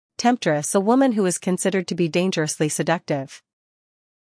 英音/ ˈtemptrəs / 美音/ ˈtemptrəs /